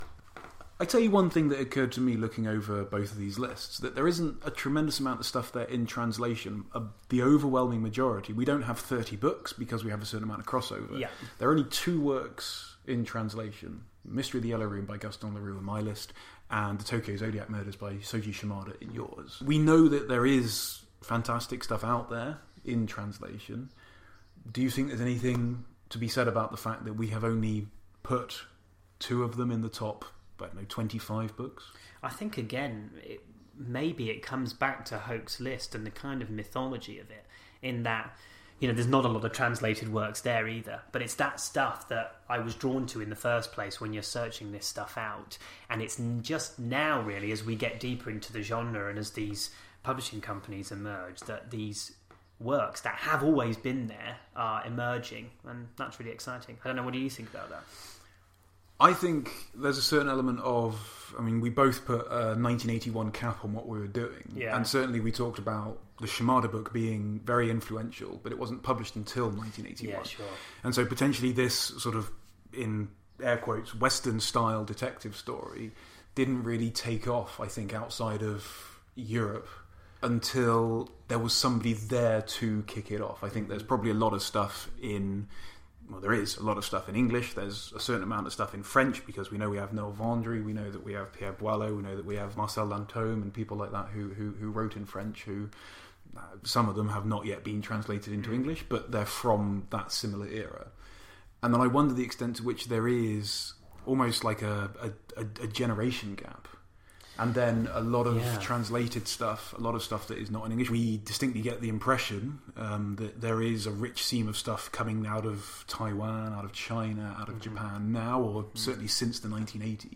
We did, however, want to share this snippet of conversation because, well, we found it interesting, and would welcome any views anyone has.